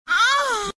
Download Moan sound effect for free.
Moan